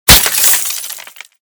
/ gamedata / sounds / material / bullet / collide / glass01hl.ogg 22 KiB (Stored with Git LFS) Raw History
glass01hl.ogg